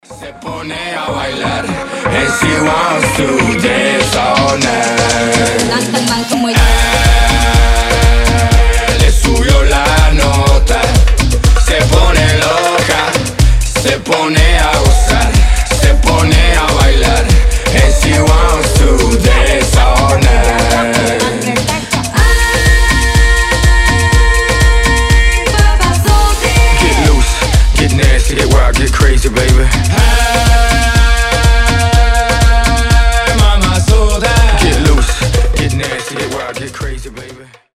dancehall
Заводной испанский рингтон